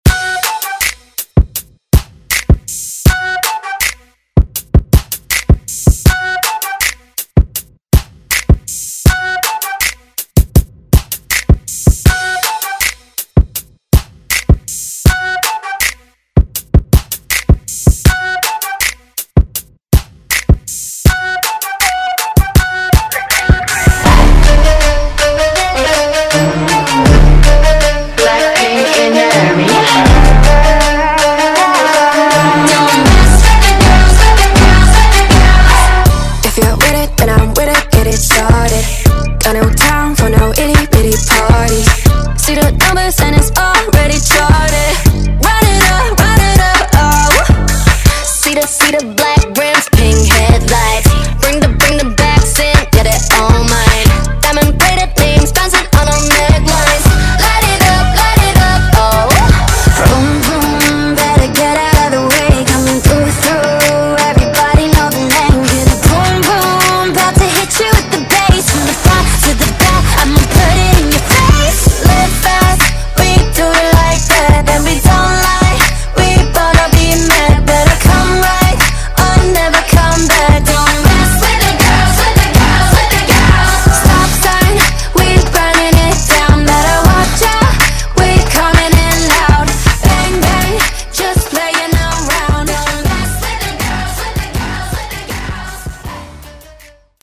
Genre: FUTURE HOUSE
Clean BPM: 128 Time